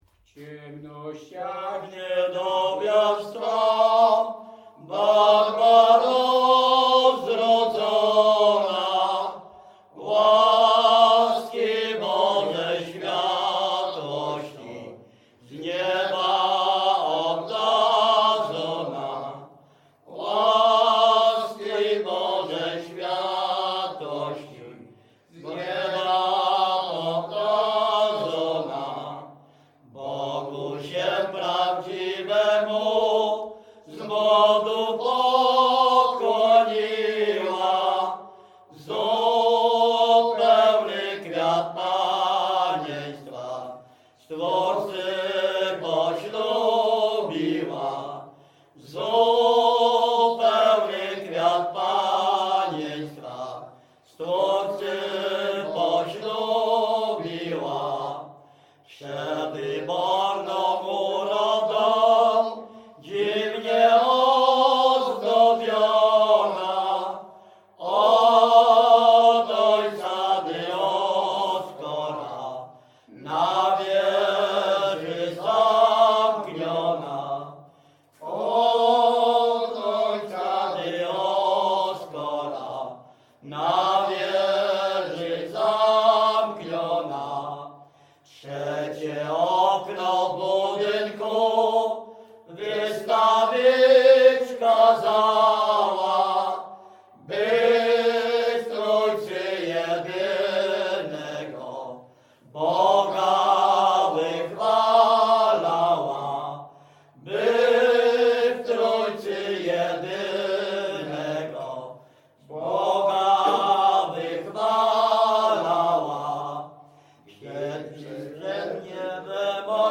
Śpiewacy z Ruszkowa Pierwszego
Wielkopolska, powiat kolski, gmina Kościelec, wieś Ruszków Pierwszy
Godzinki do świętej Barbary
Array o świętych nabożne katolickie pogrzebowe godzinki